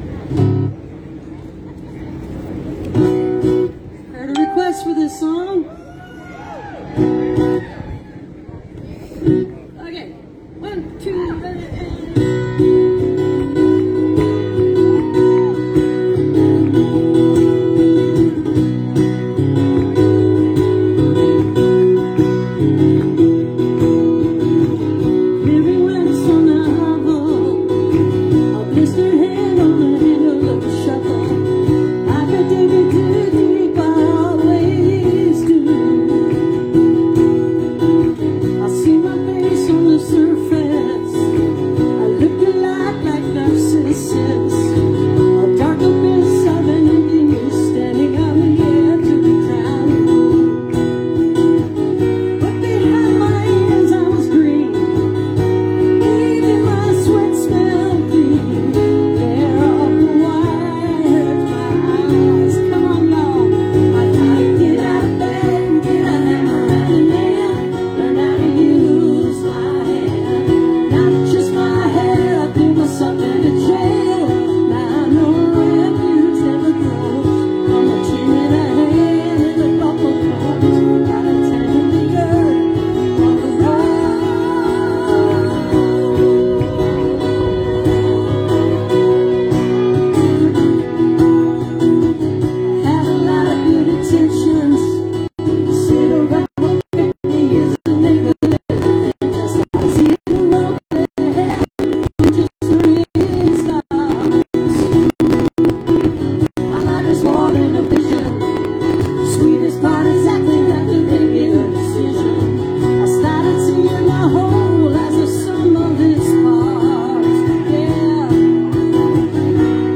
(captured from a facebook livestream)